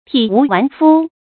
注音：ㄊㄧˇ ㄨˊ ㄨㄢˊ ㄈㄨ
體無完膚的讀法